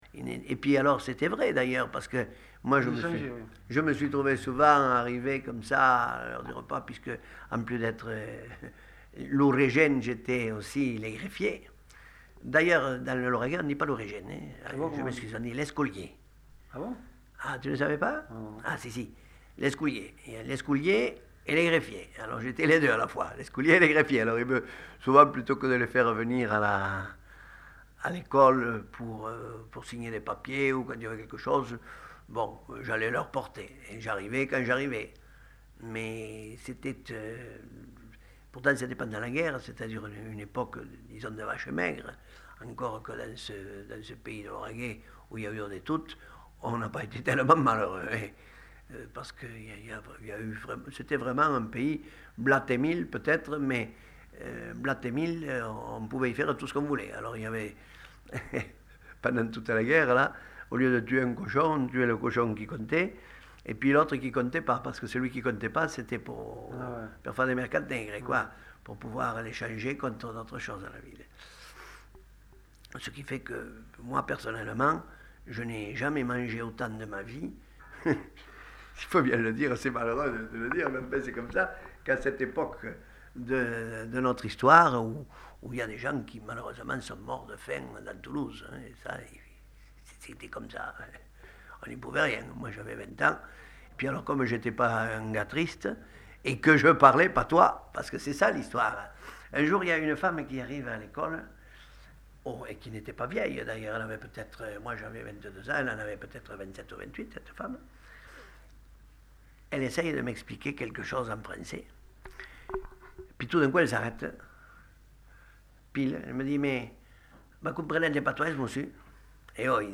Aire culturelle : Lauragais
Genre : récit de vie